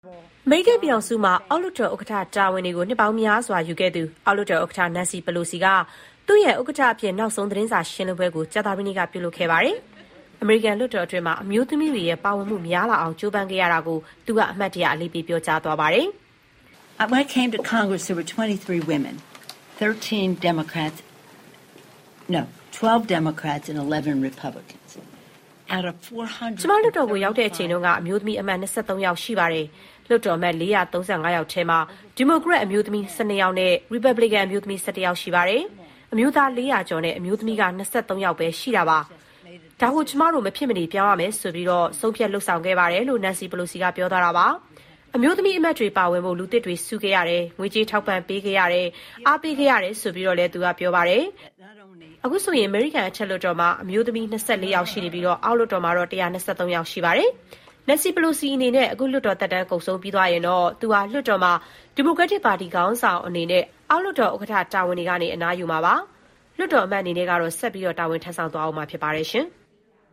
Nancy Pelosi အောက်လွှတ်တော်ဥက္ကဋ္ဌအဖြစ် နောက်ဆုံးသတင်းစာရှင်းပွဲ